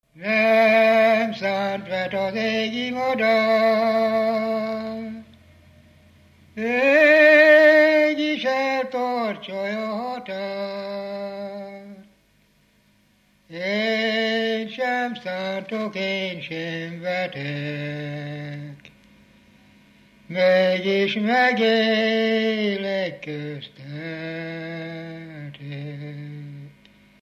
Felföld - Heves vm. - Poroszló
ének
Stílus: 1.2. Ereszkedő pásztordalok
Szótagszám: 8.8.8.8
Kadencia: 7 (4) b3 1